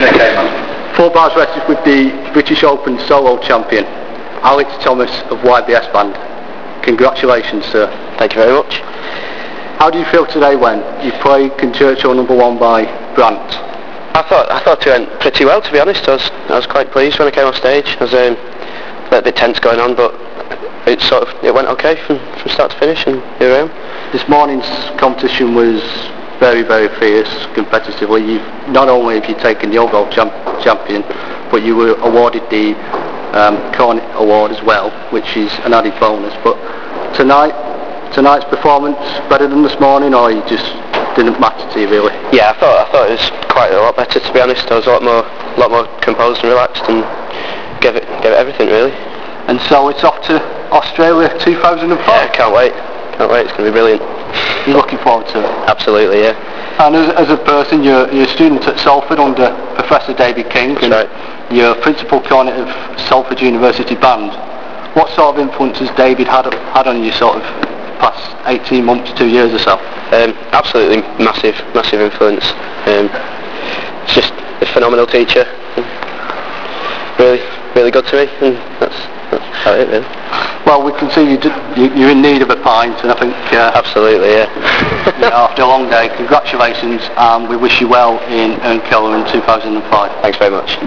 Hyde Town Hall, Sunday 7th November 2004
Audio interviews
Interview